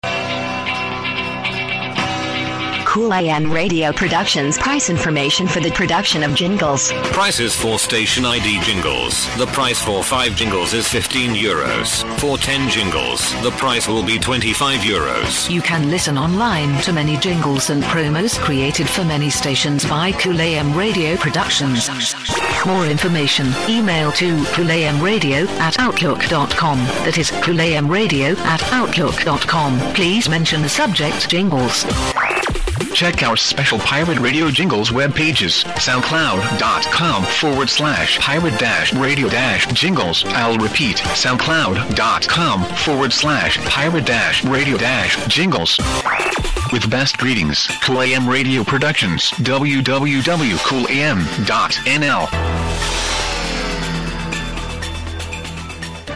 ©2014 – PROMO/COMMERCIAL – COOLAM RADIO PRODUCTIONS 03